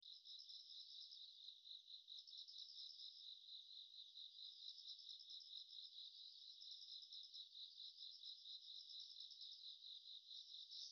例として松ヶ崎キャンパスで録音した10秒程度の虫の鳴き声（bugs.wav）をバンドパスフィルタ処理します。
図2ではわからなかった特徴として、3000 Hzから4000 HZ帯では継続的な鳴き声があり、 4500 Hzから6000 Hz帯で断続的な鳴き声があることがわかります。
室外機などの低周波成分 が無くなり、鈴虫の鳴き声だけがよく聞き取ることができました。
図4：処理後の鈴虫の鳴き声のスペクトログラム
filtered_bugs.wav